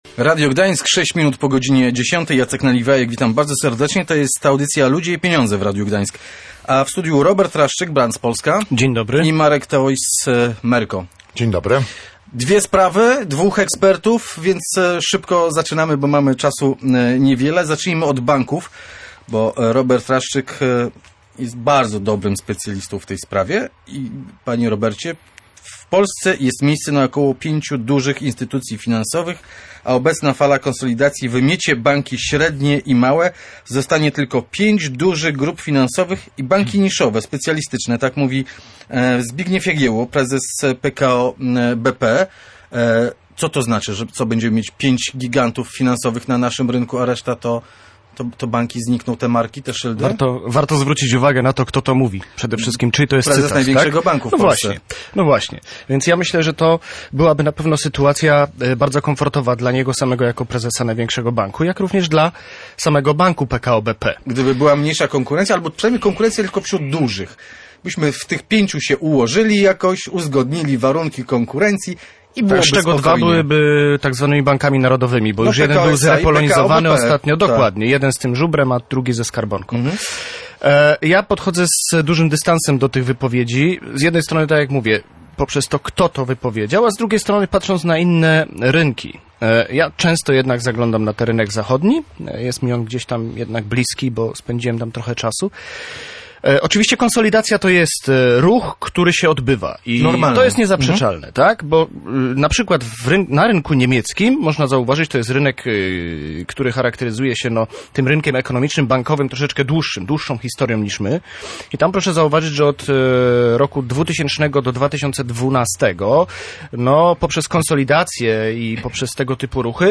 Czy to oznacza, że na rynku pozostanie tylko pięć gigantów finansowych? Między innymi na to pytanie odpowiadali goście audycji Ludzie i Pieniądze.